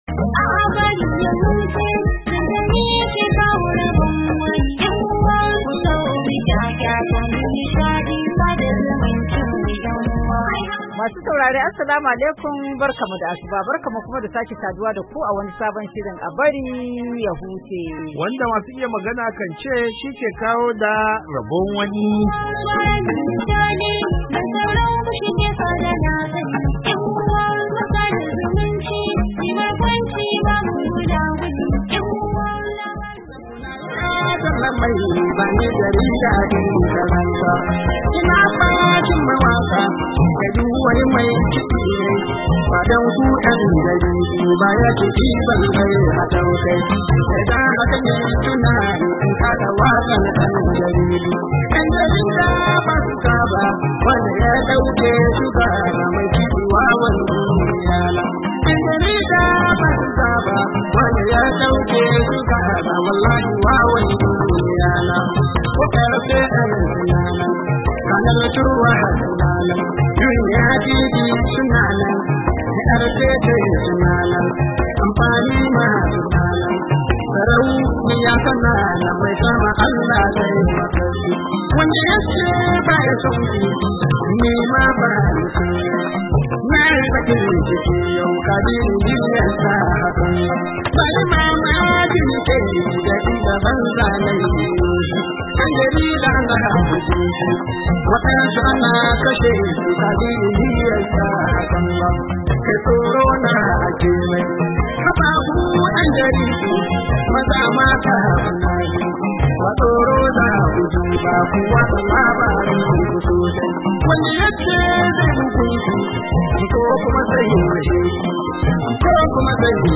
A BARI YA HUCE: Hira